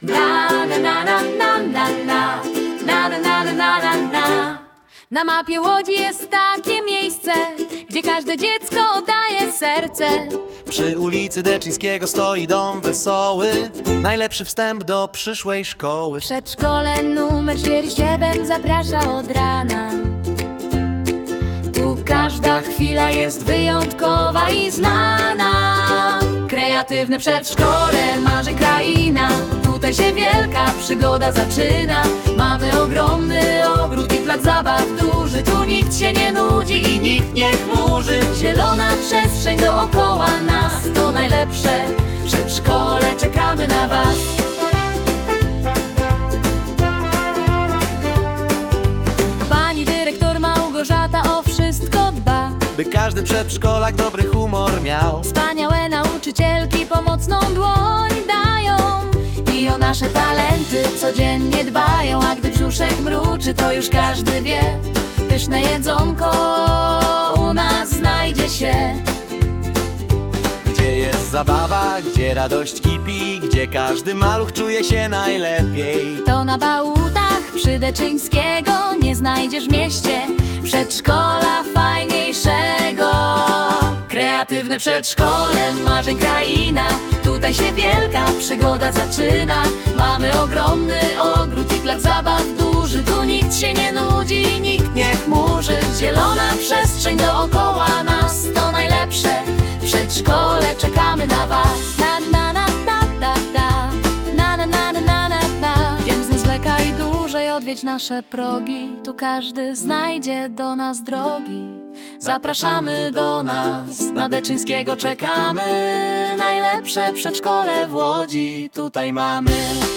To radosny utwór, który najlepiej oddaje atmosferę naszego miejsca – pełnego uśmiechu, zabawy i dziecięcej energii.
Gwarantujemy dużo pozytywnej energii, uśmiechu i melodii, która na długo zostaje w pamięci.
Posłuchaj naszej piosenki i przekonaj się, jak radośnie brzmi nasze przedszkole!